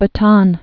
(bə-tän)